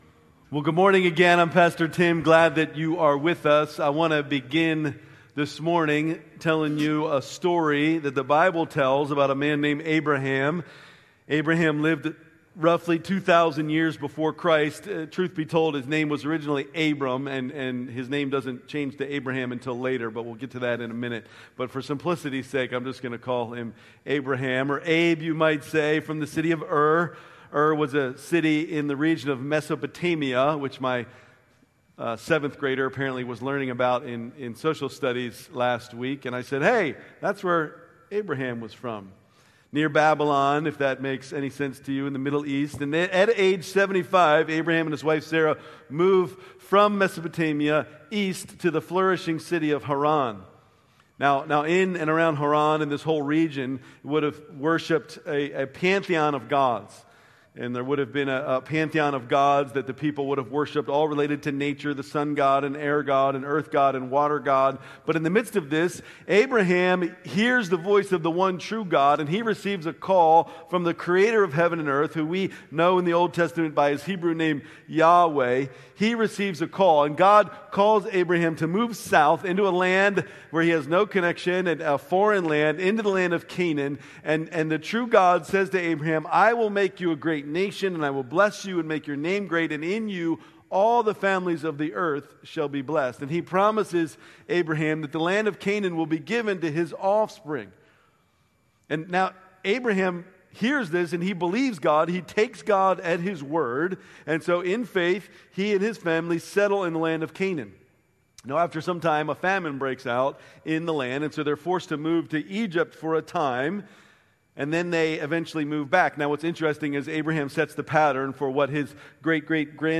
October 26, 2025 Worship Service Order of Service: